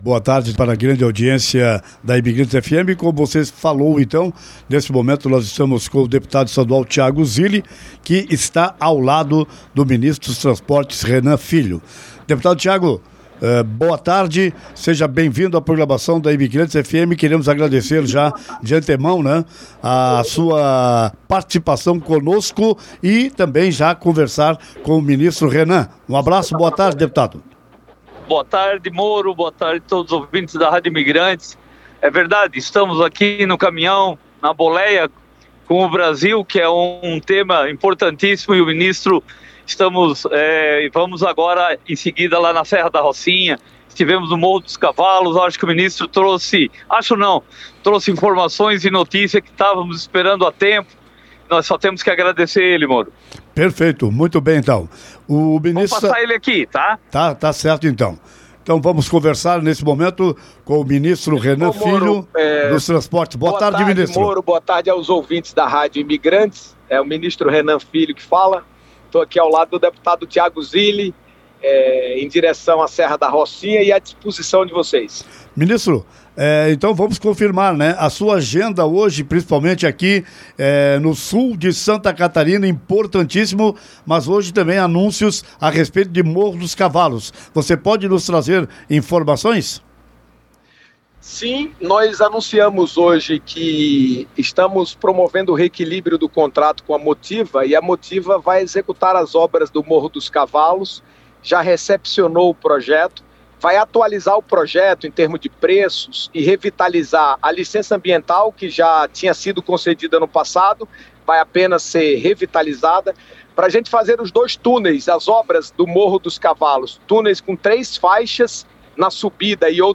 O ministro dos Transportes, Renan Filho, visita a obra da Serra da Rocinha com vistas a marcar a data de inauguração e durante a vinda junto com o deputado estadual, Tiago Zilli, concedeu entrevista para a rádio Imigrantes.